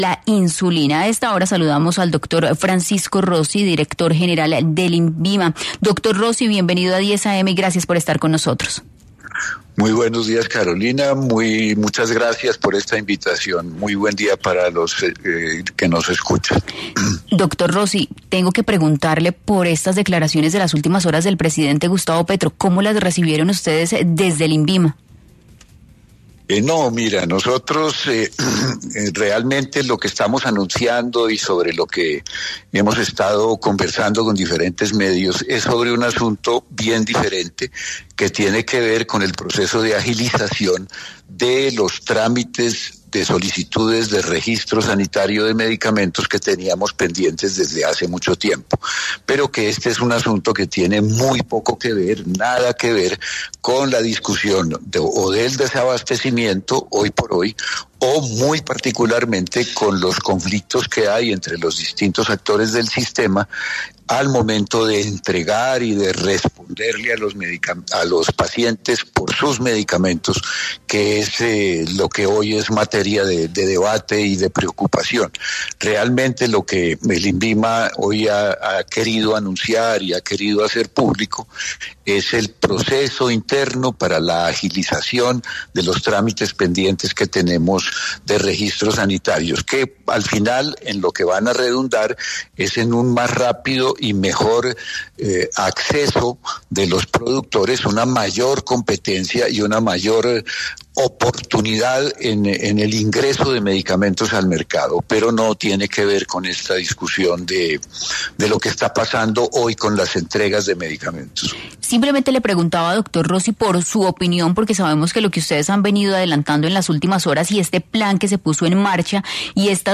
En 10AM de Caracol Radio habló Francisco Rossi, director del INVIMA Colombia, quien enfatizó que se trata de casos muy puntuales de medicamentos